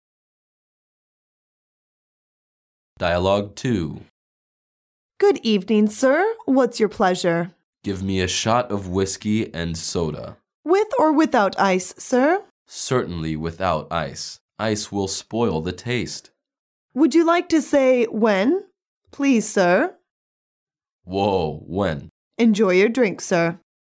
Dialouge 2